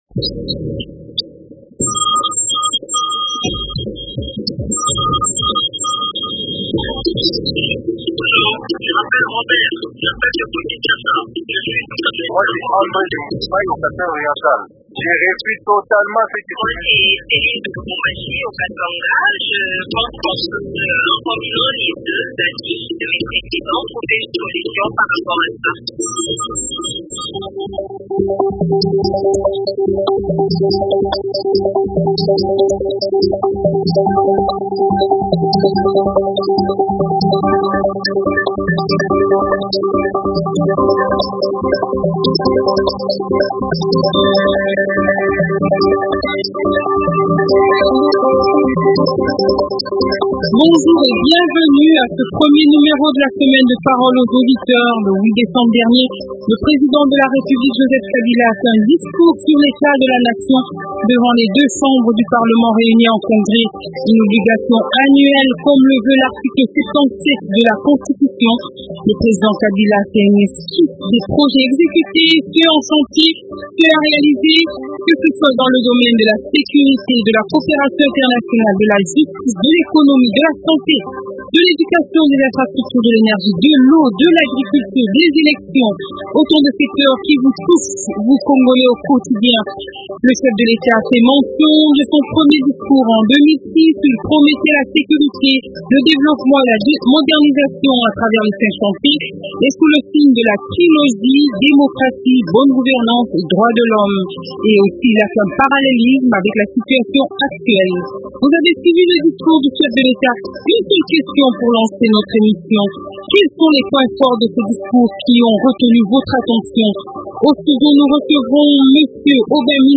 Le 8 décembre dernier le président de la République démocratique du Congo, Joseph Kabila, a fait un discours sur l’état de la nation devant les deux chambres du parlement réunies en congrès.
Invité: Aubin Minaku, député national de la majorité présidentielle et vice président du groupe parlementaire PPRD à l’assemblée nationale.